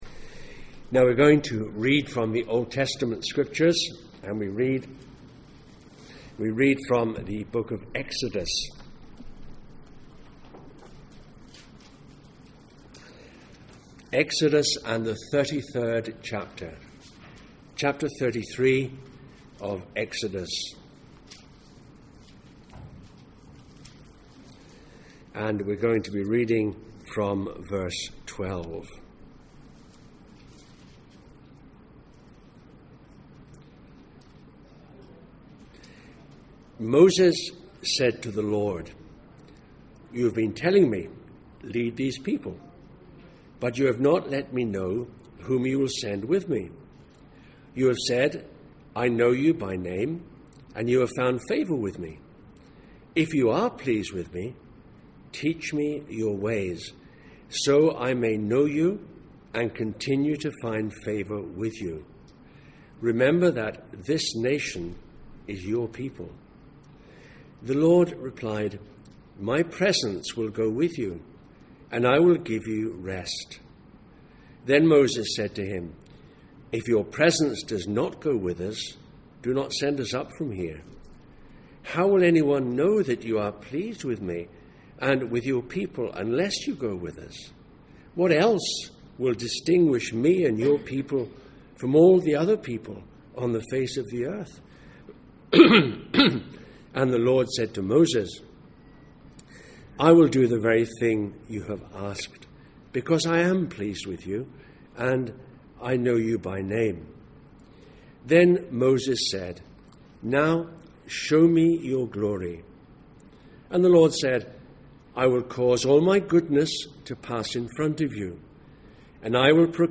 Passage: Exodus 33:12-23, John 1:1-18, John 14:1-4, Luke 11:53 Service Type: Sunday Morning